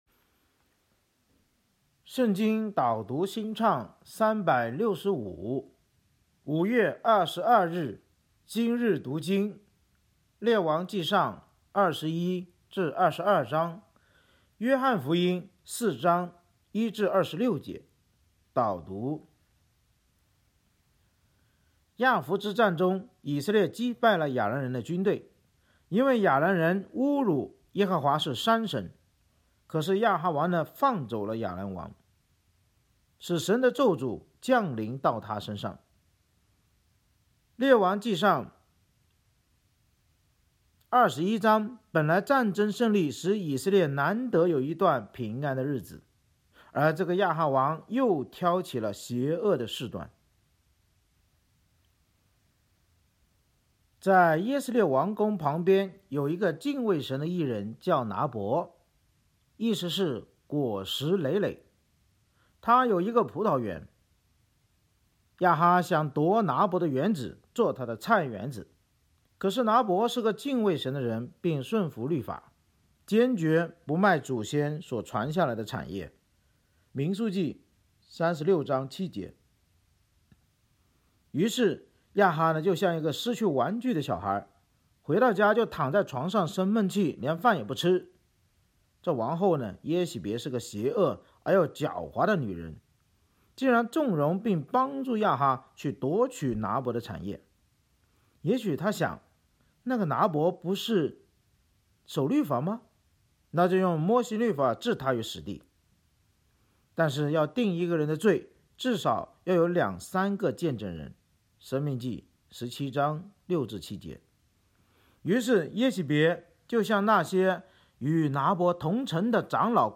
圣经导读&经文朗读 – 05月22日（音频+文字+新歌）